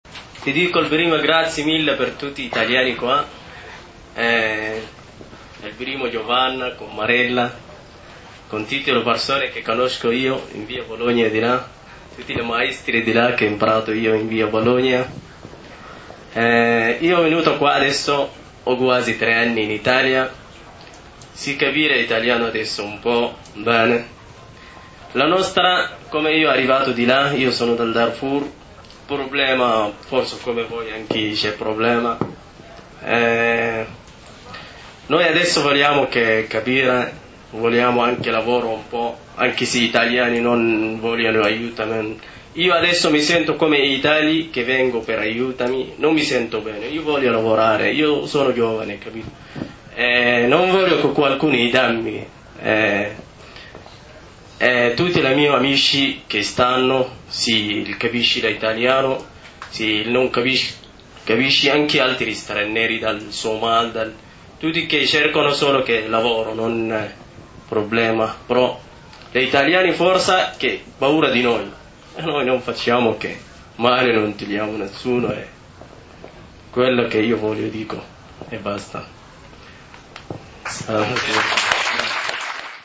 Venerdì 5  Novembre, ore 20:45 - Circolo Anatra Zoppa, via Courmayeur 5
Intevento 3 dal pubblico